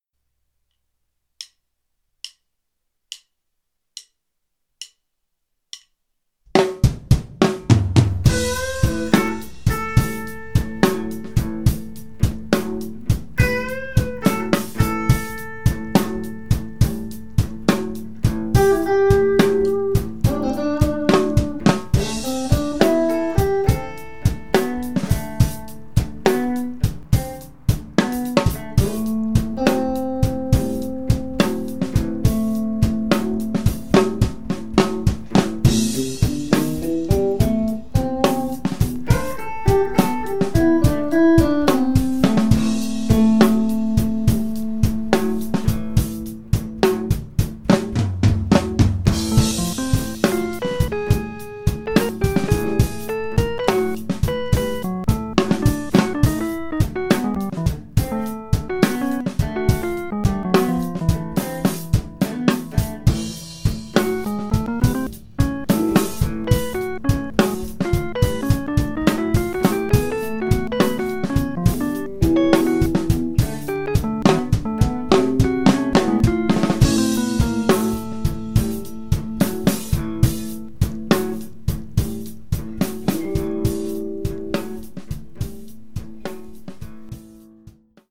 We recorded an original musical piece (lead guitar, rhythm guitar and drums) and used it as the input of our system. We produced an inspired composition and recorded it using Csound. The resulting audio was superposed on the original recording after the lead guitar stops playing (0:49) to emulate a jam session.
fantasia-guitarra-ordenador.mp3